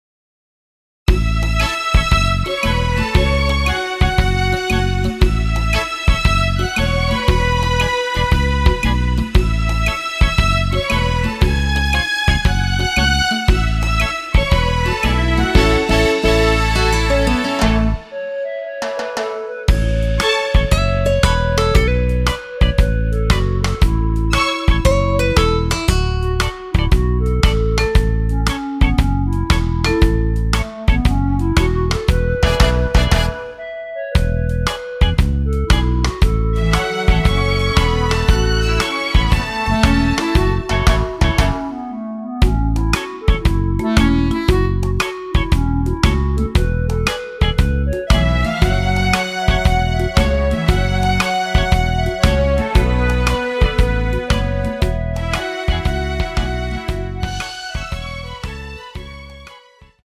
원키에서(-7)내린 멜로디 포함된 MR입니다.
앞부분30초, 뒷부분30초씩 편집해서 올려 드리고 있습니다.
중간에 음이 끈어지고 다시 나오는 이유는
곡명 옆 (-1)은 반음 내림, (+1)은 반음 올림 입니다.
(멜로디 MR)은 가이드 멜로디가 포함된 MR 입니다.